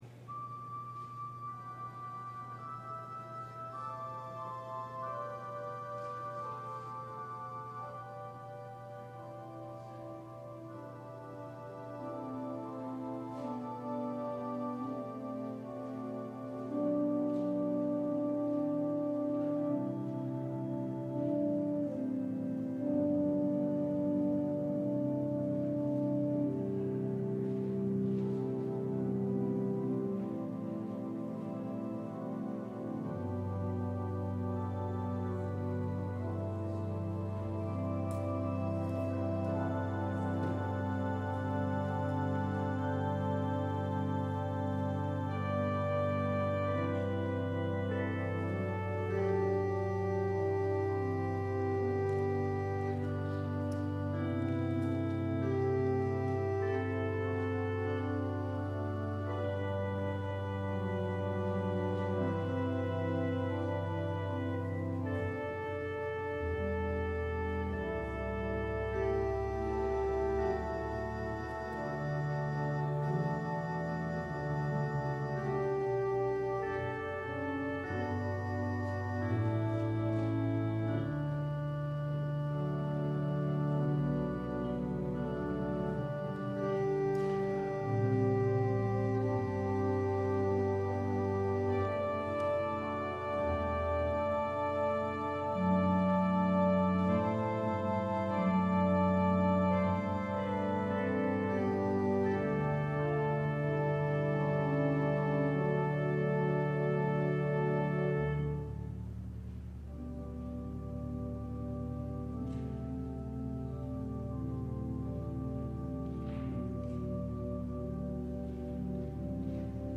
LIVE Evening Worship Service - Paul’s Slideshow
Congregational singing—of both traditional hymns and newer ones—is typically supported by our pipe organ.